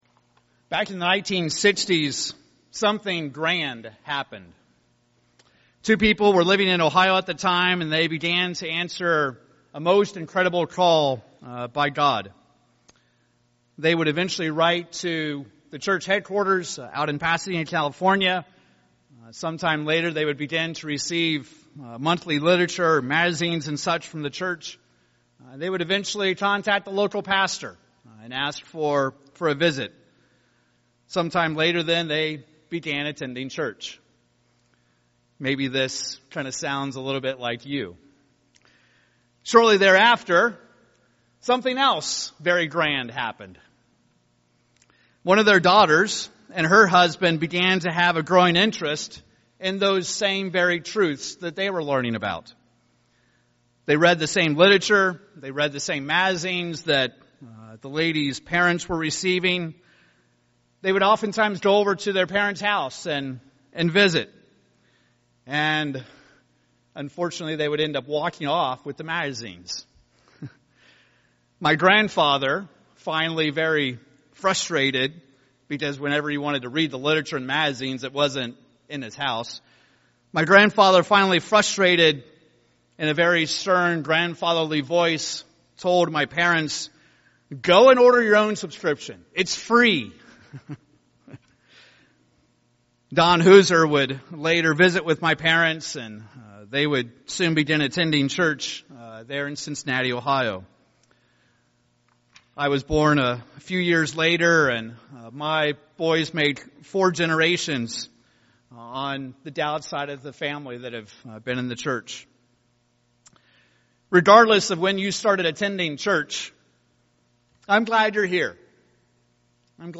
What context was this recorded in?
Given in Salina, KS Tulsa, OK Wichita, KS